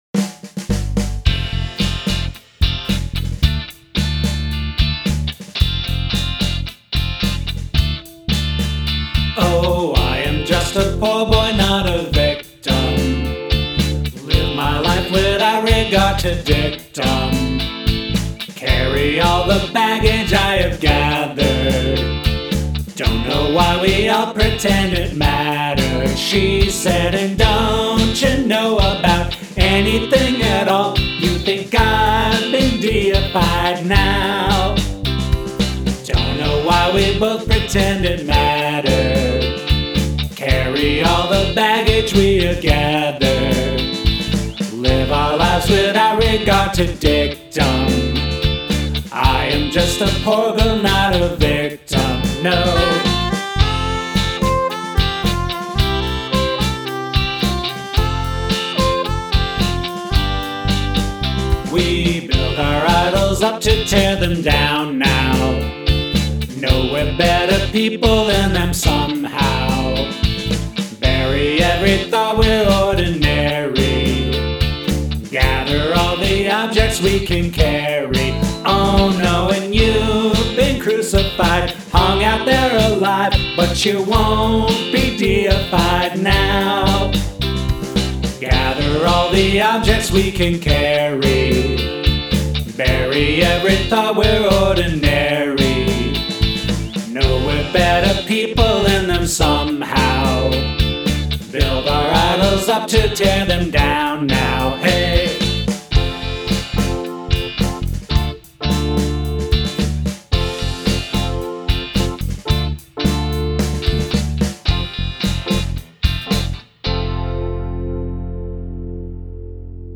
A pattern: CD FG AA GF DC
The chorus / B section melody is pretty catchy.